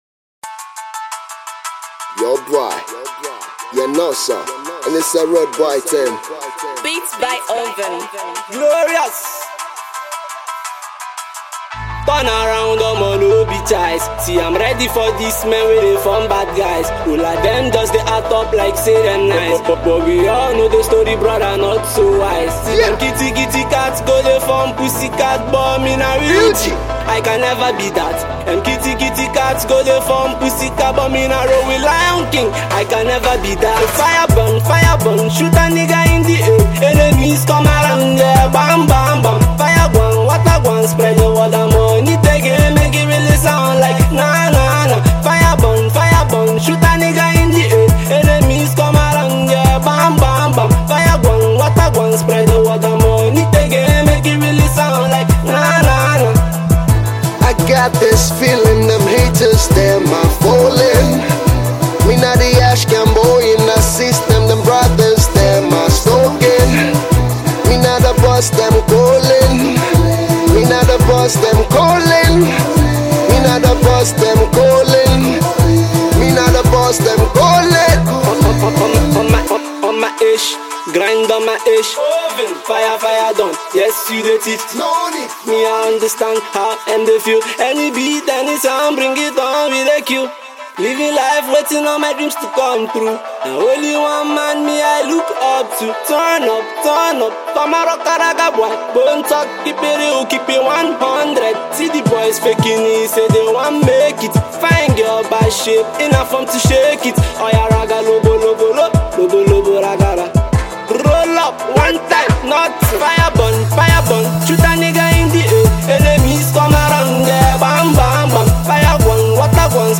afro-pop blended song
reggae